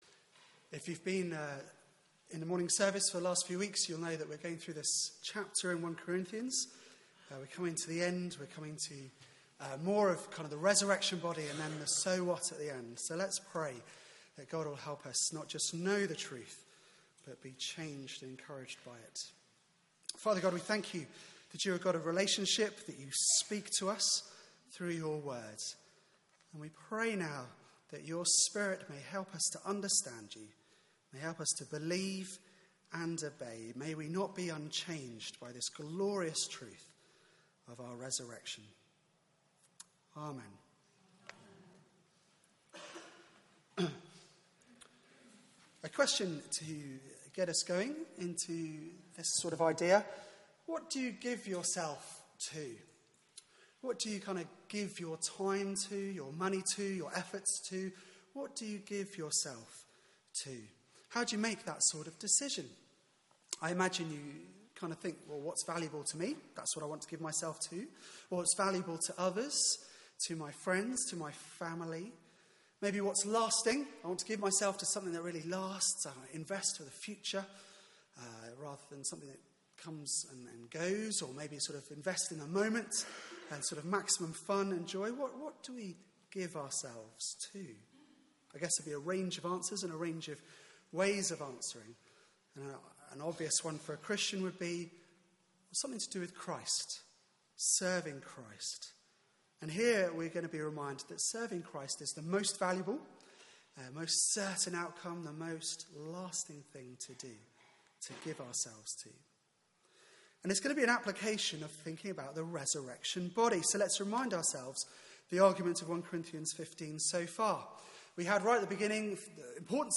Theme: Death defeated Sermon